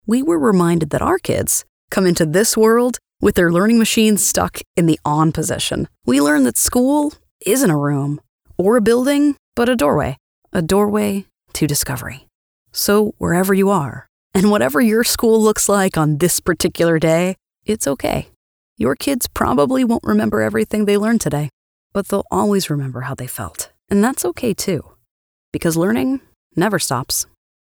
Englisch (Amerikanisch)
Kommerziell, Junge, Natürlich, Freundlich, Corporate
Unternehmensvideo